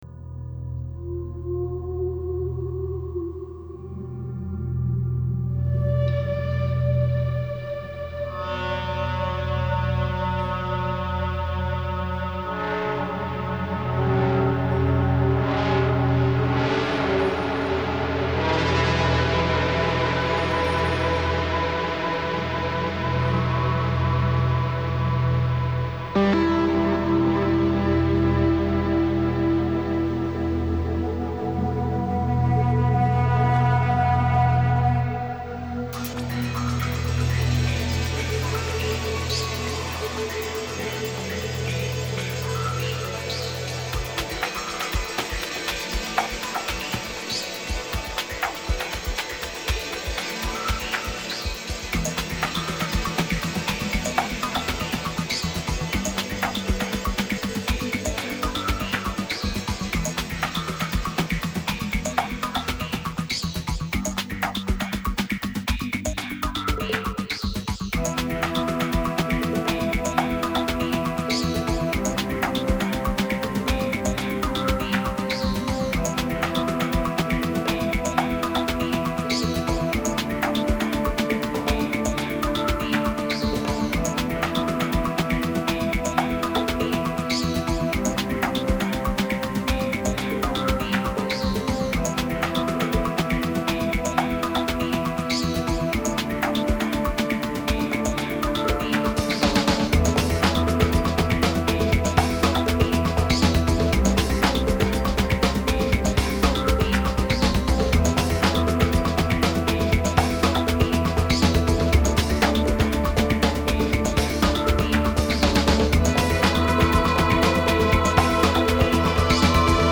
Нью эйдж New age Музыка нью эйдж